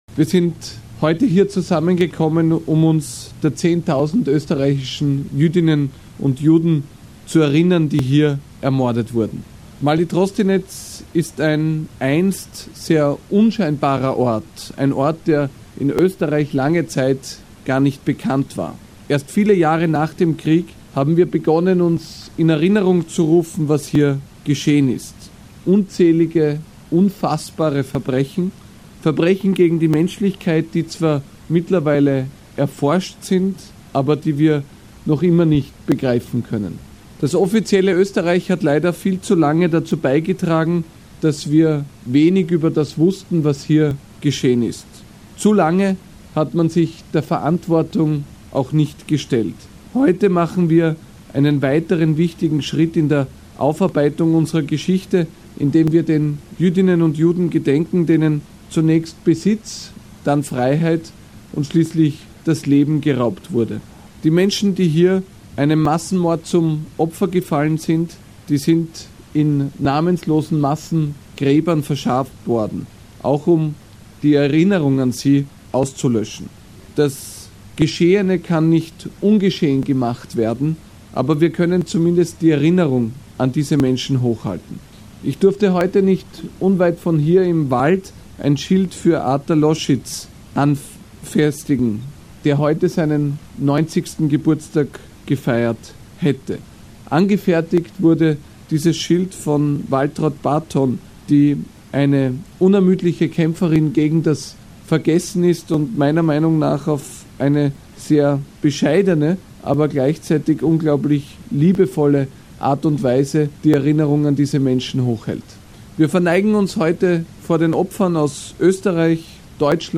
An der Eröffnungszeremonie des Holocaust-Mahnmals „Das Massiv der Namen“nahm auch der Bundeskanzler von Österreich Sebastian Kurz teil.